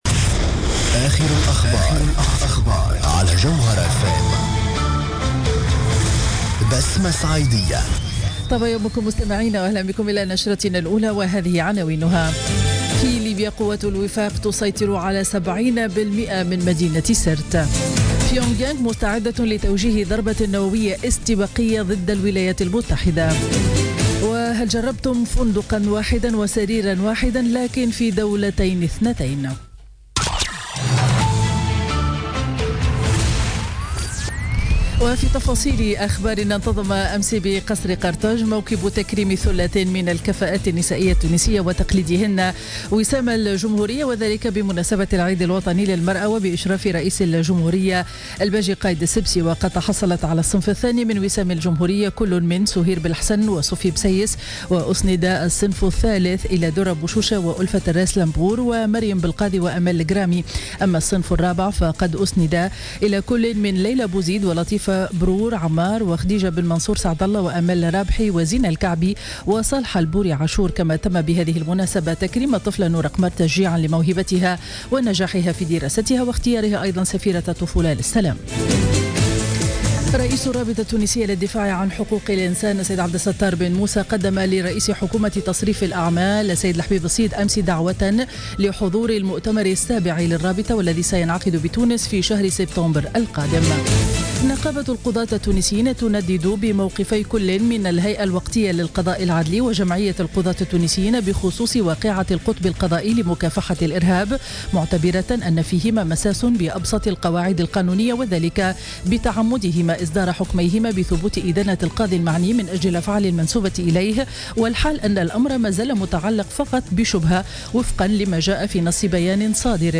Journal Info 07h00 du Dimanche 14 Août 2016